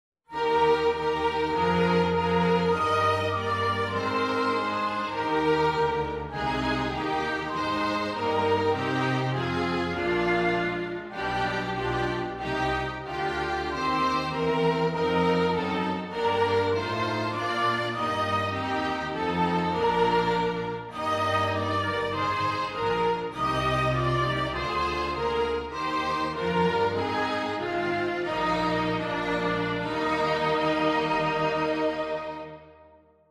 Flexible Ensemble (Woodwind, Brass and String instruments)
(Woodwind and Strings)